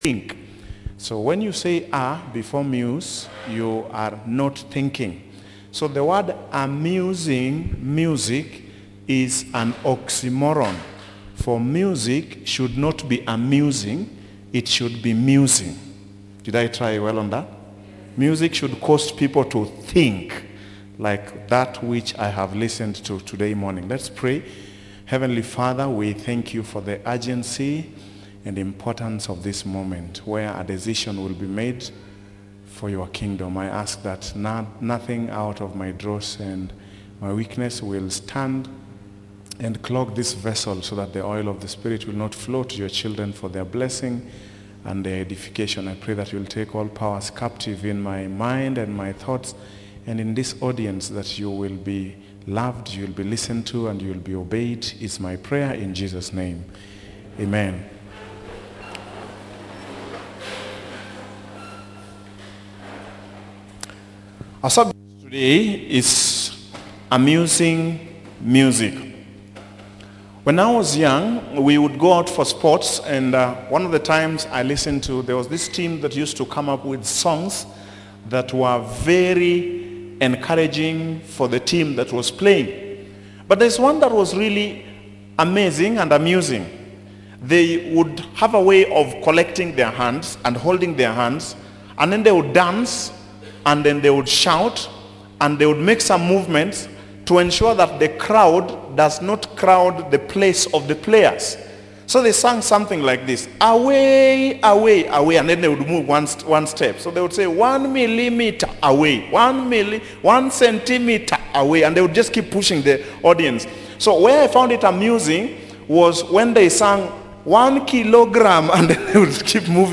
29655-Sabbath_Sermon.mp3